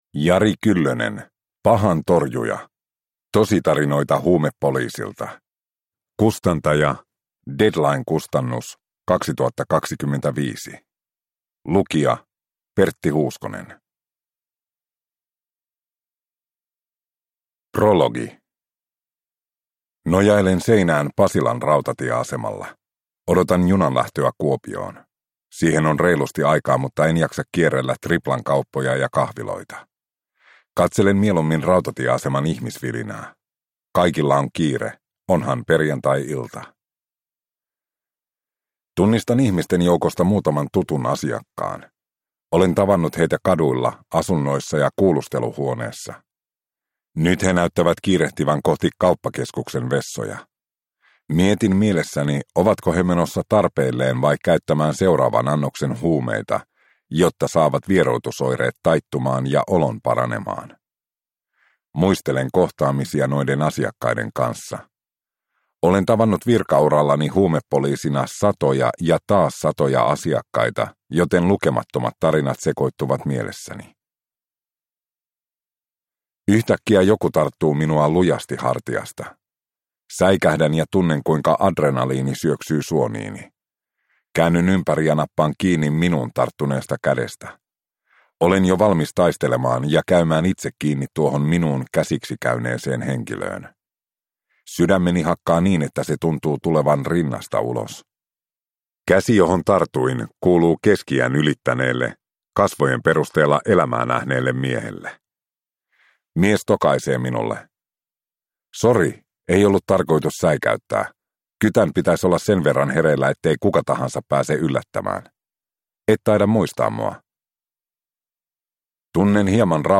Pahan torjuja – Ljudbok